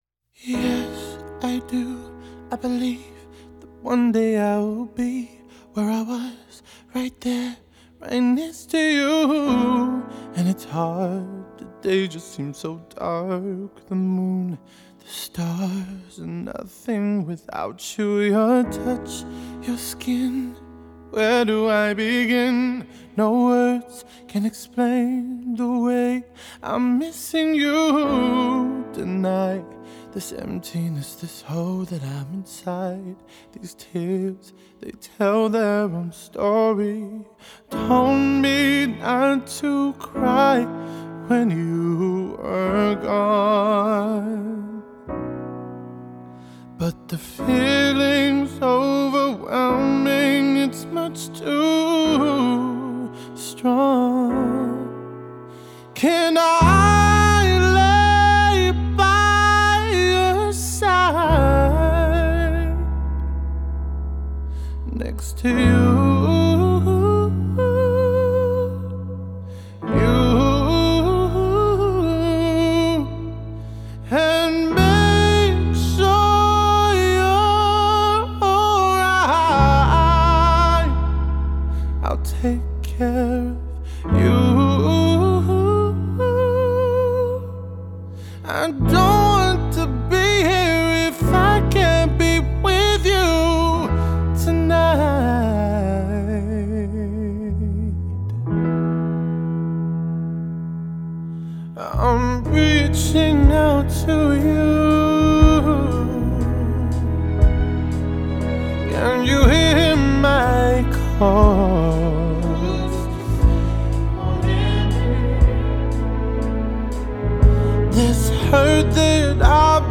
songs of deep melismatic male longing